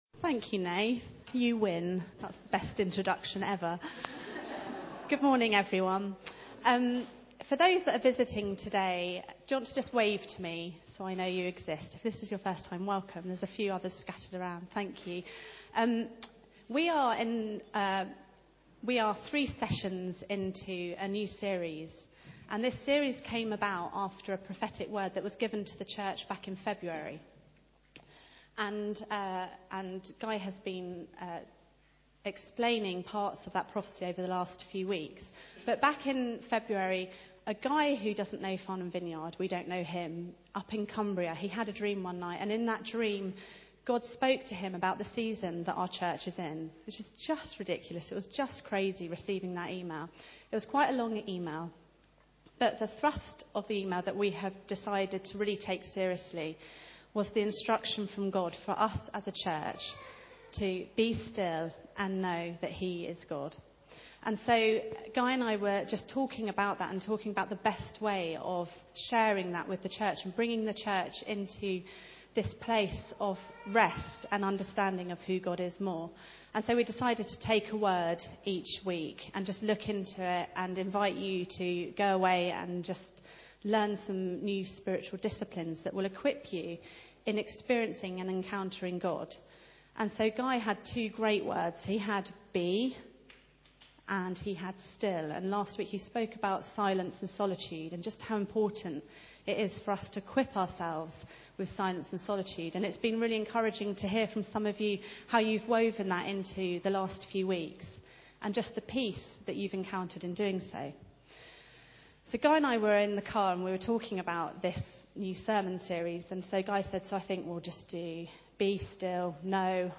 Service Type: Sunday Meeting